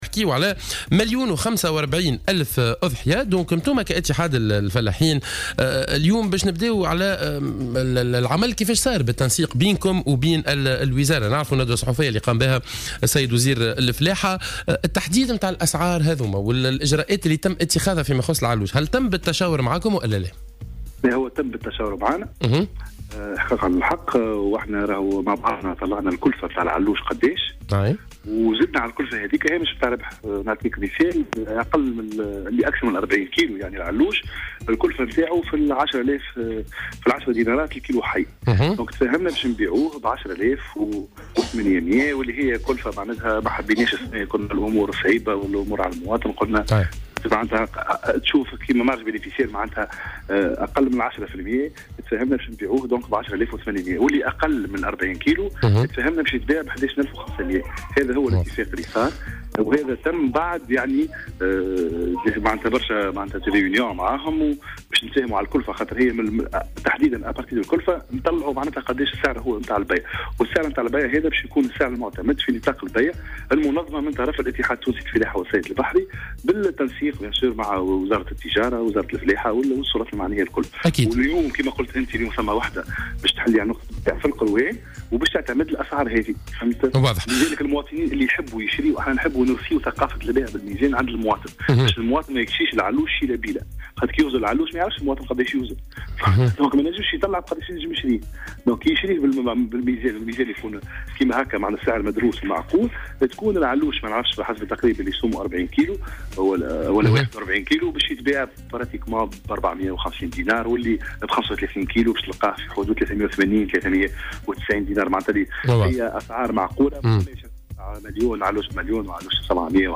في مداخلة له